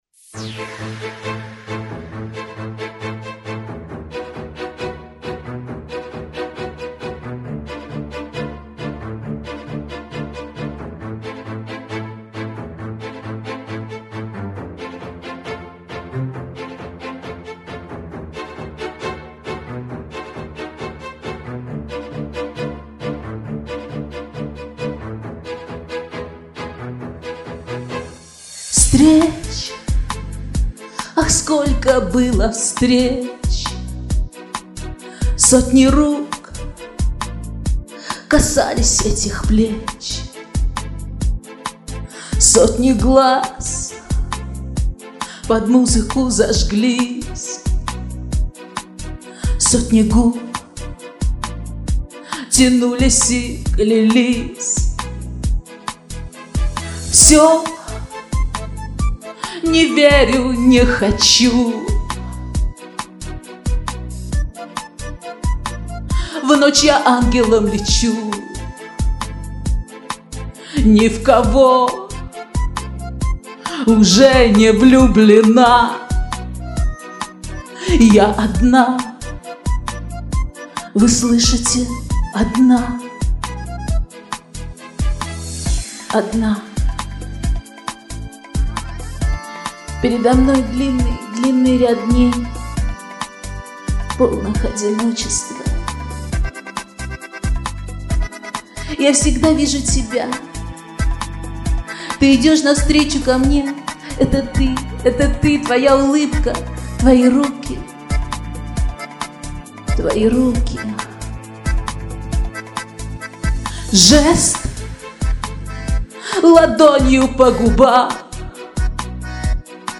Слева нет реверберации.